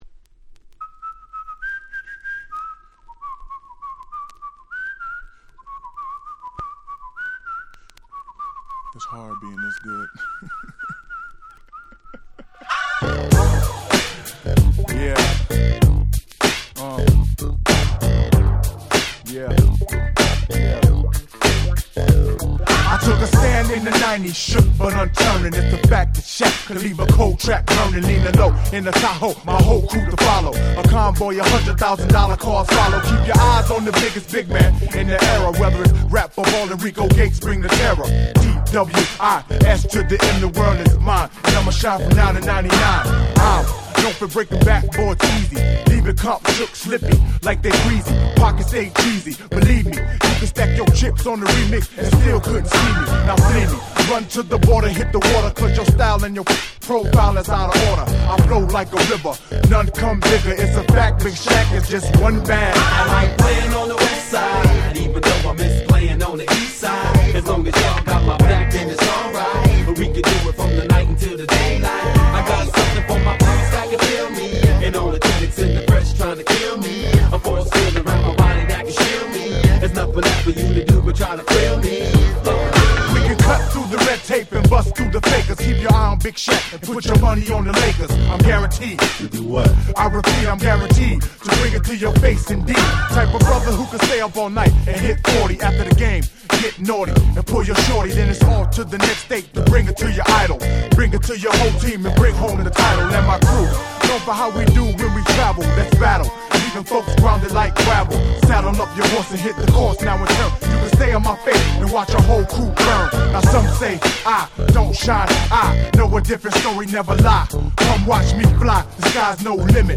96' Very Nice West Coast Hip Hop !!
Talk Boxブリブリのイケイケパーティーチューンです！
90's Boom Bap ブーンバップ ウエストコースト ウエッサイ G-Rap Gangsta Rap